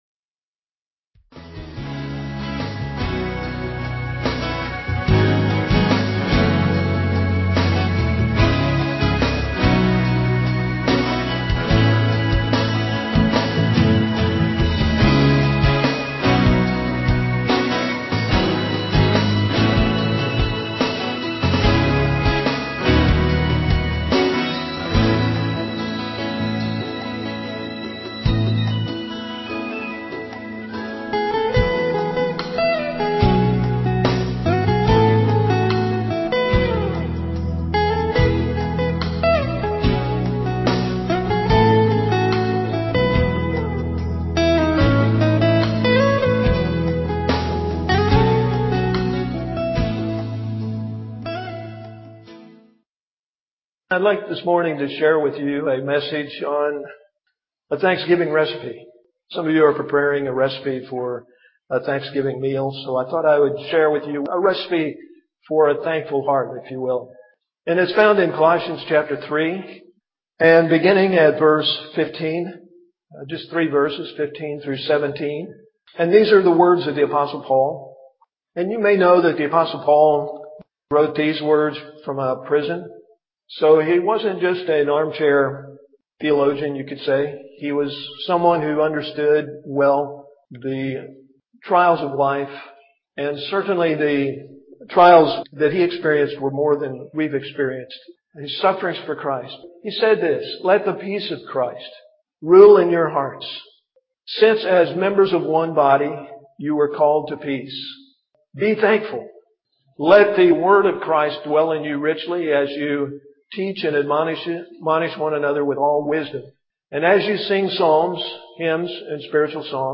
at Ewa Beach Baptist Church.